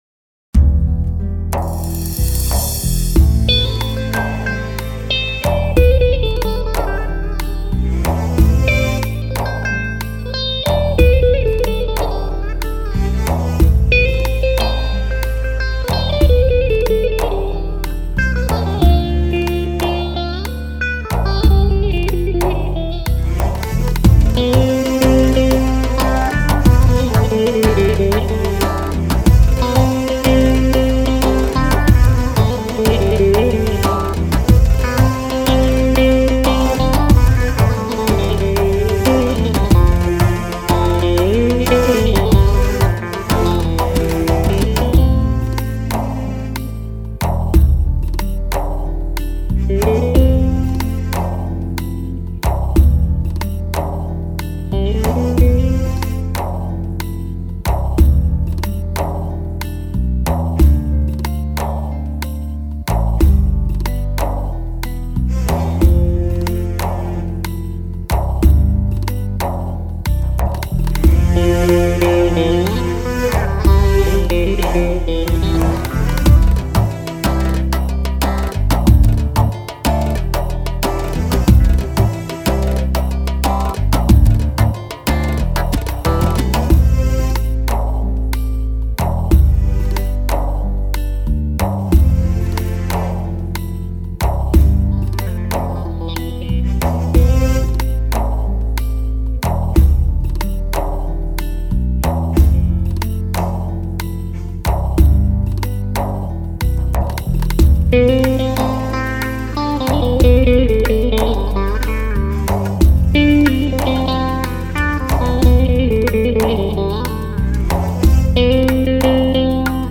Halk Müziği
DAMAR